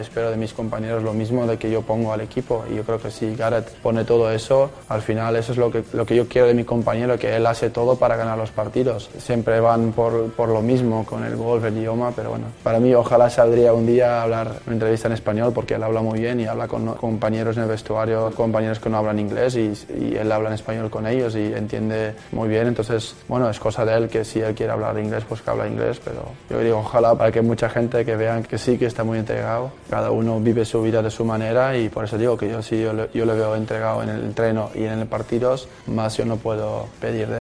Entrevista del portero del Real Madrid en MARCA, donde habló de la relación de Gareth Bale con sus compañeros.